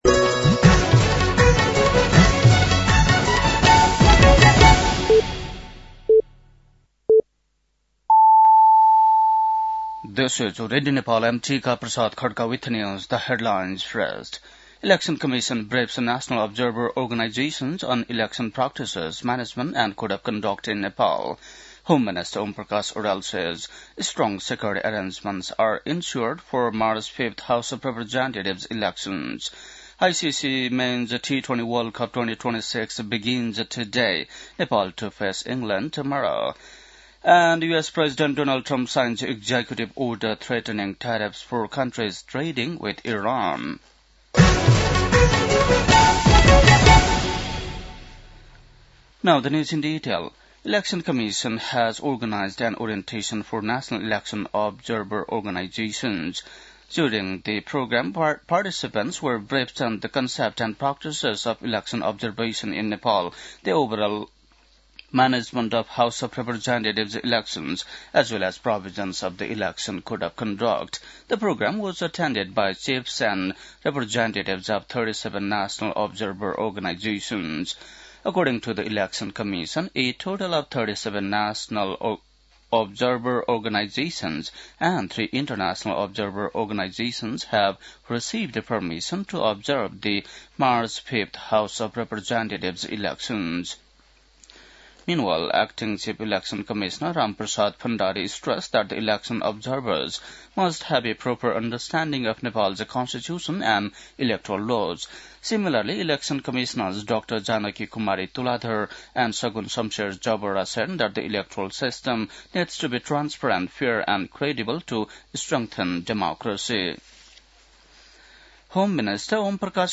बेलुकी ८ बजेको अङ्ग्रेजी समाचार : २४ माघ , २०८२
8.-pm-english-news-1-1.mp3